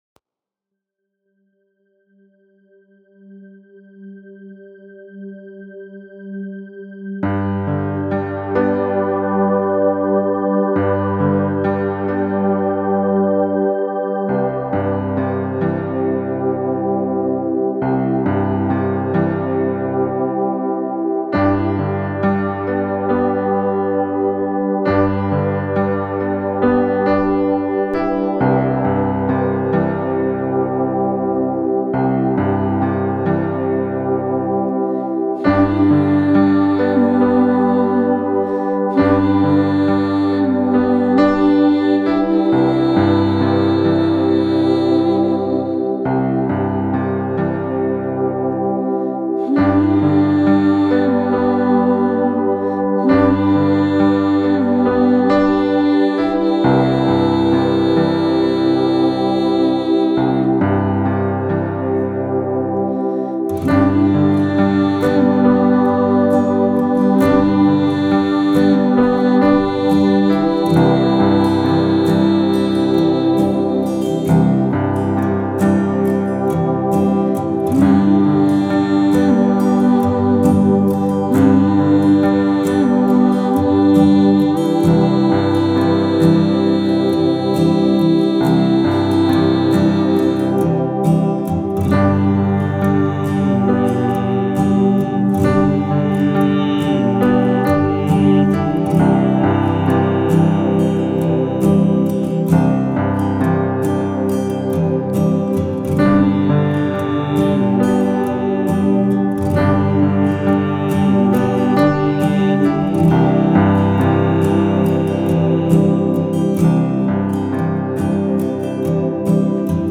РАСПЕВКА В ПОДАРОК
Слушайте мелодию и на выдохе повторяйте звуки. Сначала закрытый звук “ххммм”.
Мелодия будет меняться в процессе, слушайте и мягко повторяйте мелодию, расслабляя шею и все тело в процессе звучания.